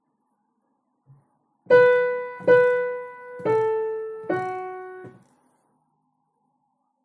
Powtarzanie głosem melodii